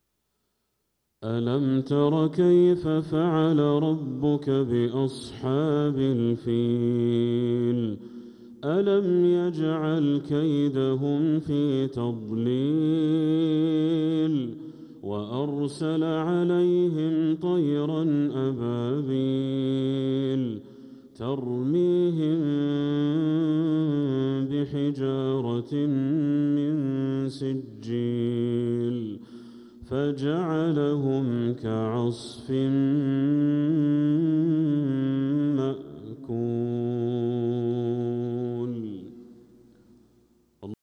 سورة الفيل كاملة | صفر 1447هـ > السور المكتملة للشيخ بدر التركي من الحرم المكي 🕋 > السور المكتملة 🕋 > المزيد - تلاوات الحرمين